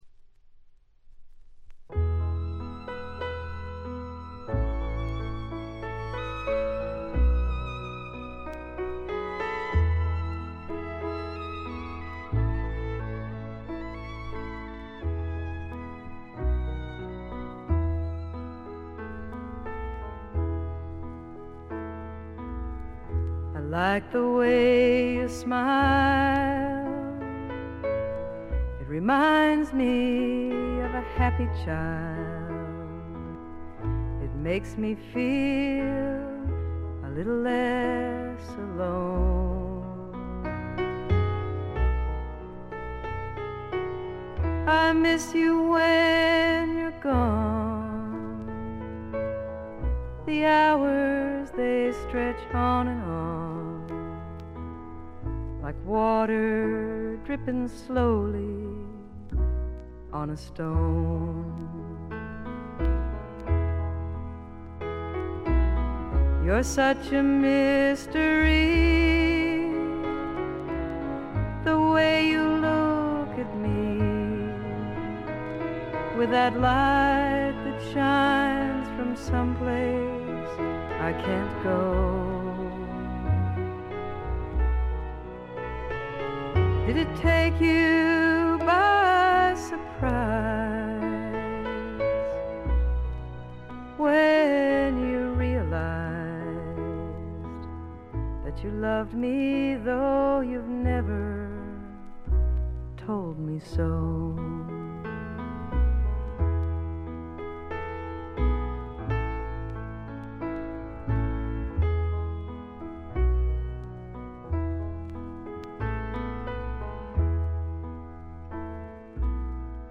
ほとんどノイズ感無し。
本作もしみじみとした歌の数々が胸を打つ女性フォーキー・シンガーソングライターの基本です。
試聴曲は現品からの取り込み音源です。